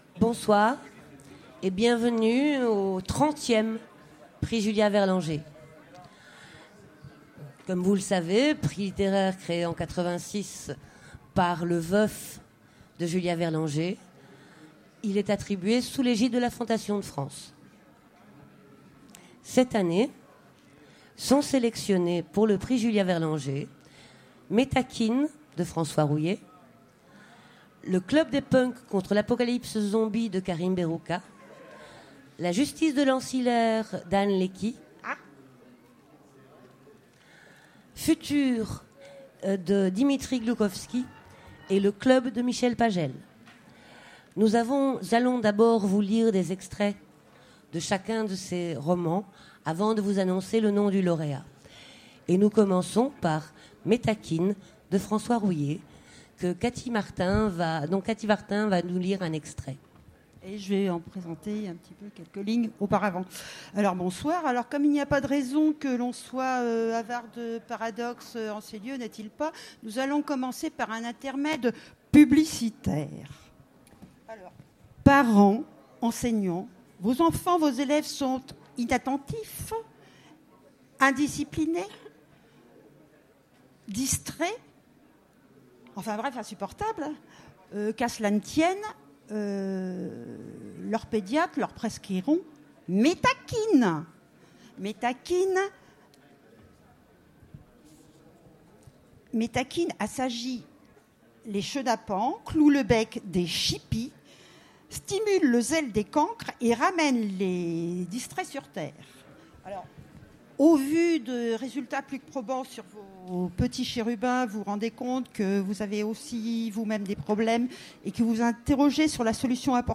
Utopiales 2016 : Remise du prix Julia Verlanger
Remise de prix Conférence